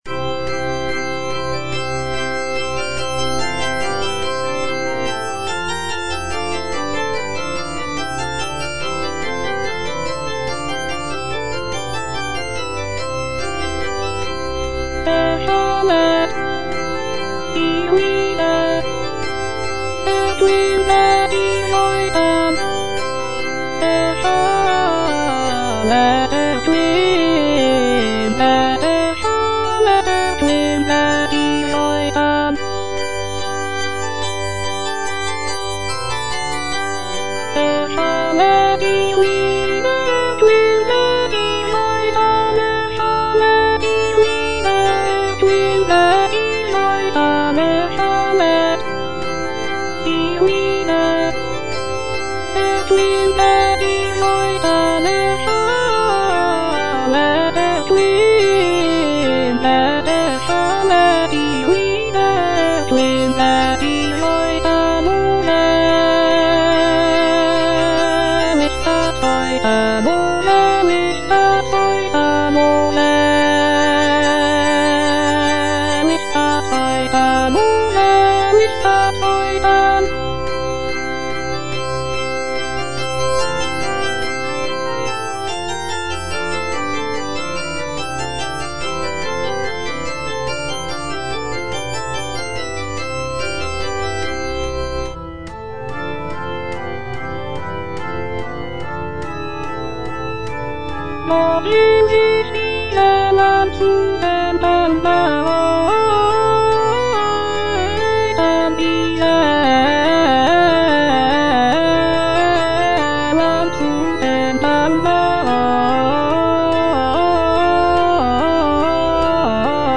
Alto (Voice with metronome) Ads stop